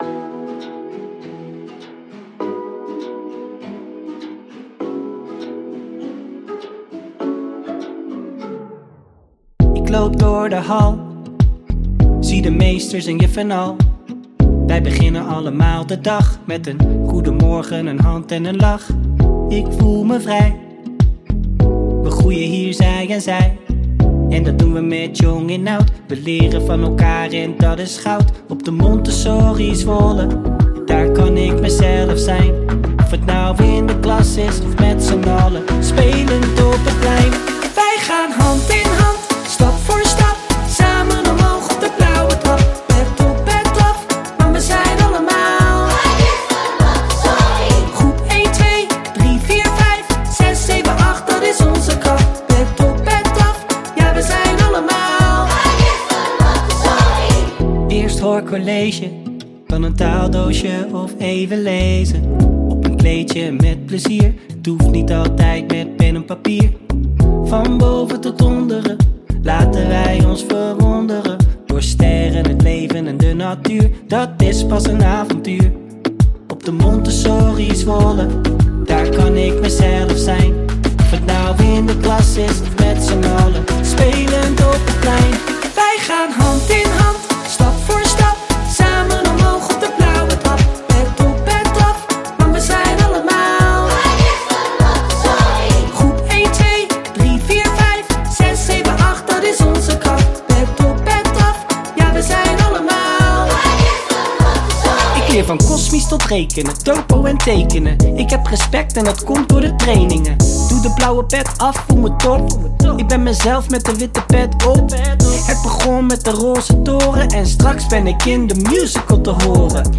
Schoollied ‘Kanjers van de Montessori’